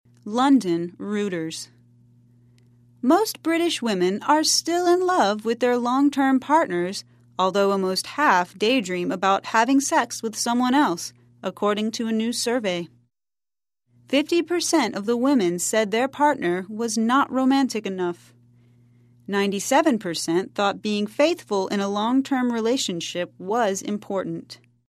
在线英语听力室赖世雄英语新闻听力通 第73期:近半数女性梦想与别人上床的听力文件下载,本栏目网络全球各类趣味新闻，并为大家提供原声朗读与对应双语字幕，篇幅虽然精短，词汇量却足够丰富，是各层次英语学习者学习实用听力、口语的精品资源。